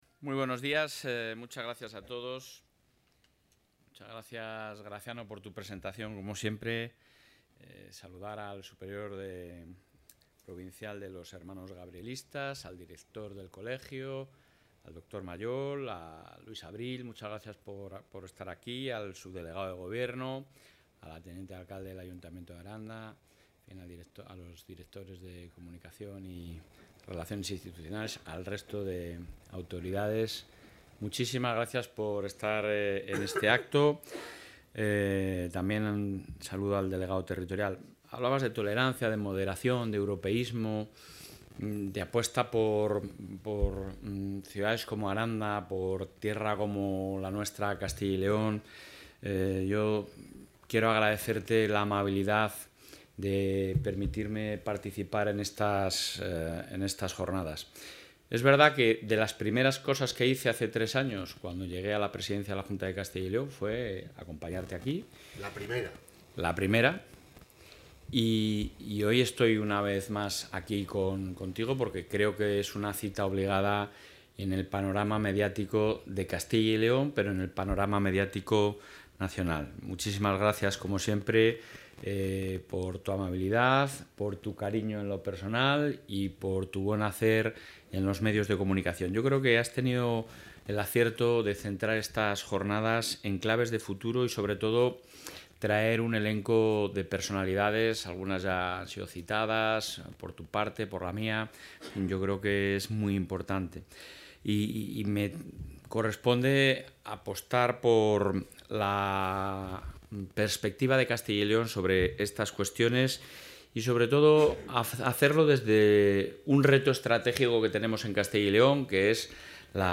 Intervención presidente.